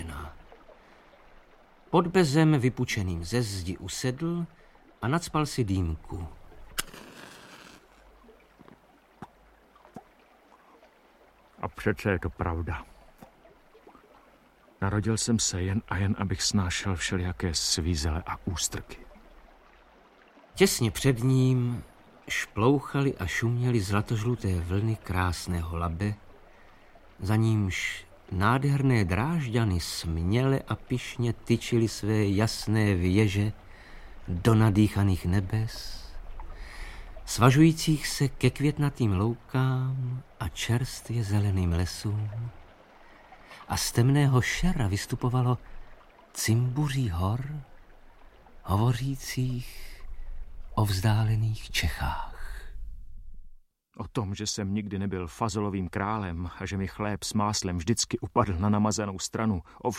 Audiobook
Read: Gabriela Vránová